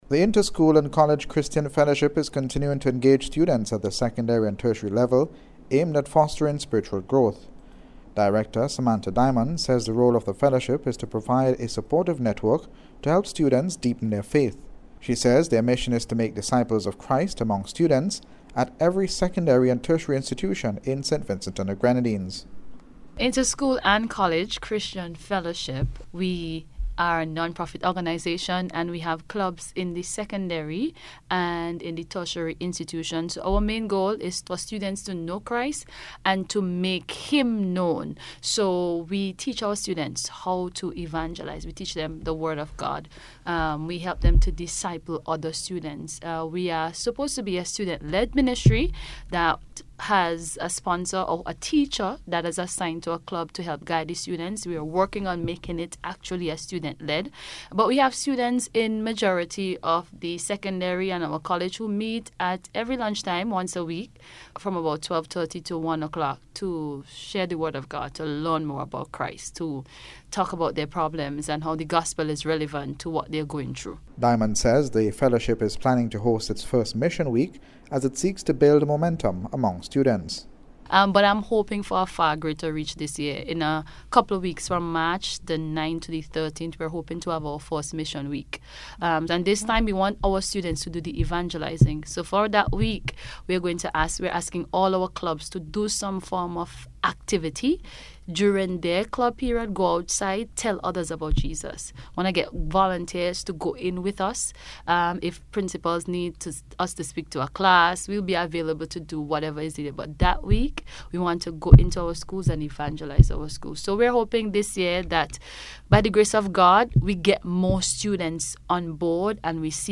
NBC’s Special Report- Tuesday 24th February,2026